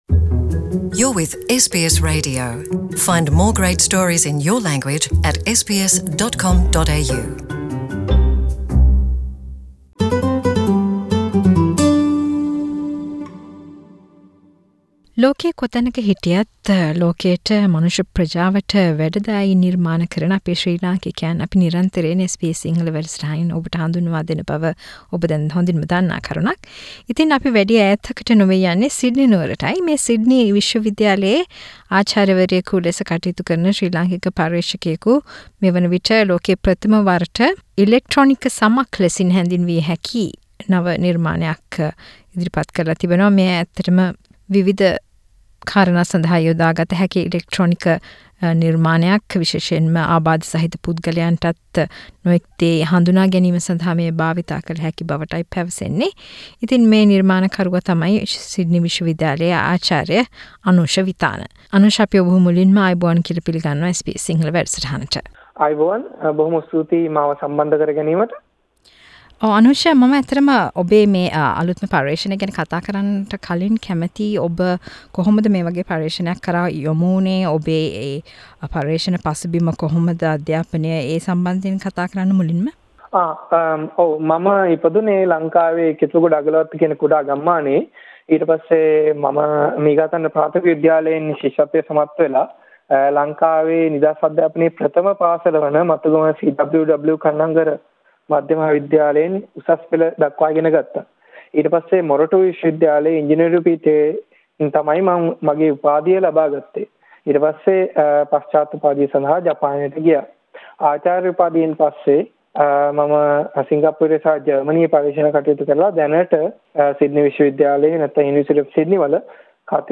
SBS සිංහල සිදු කල පිලිසදර.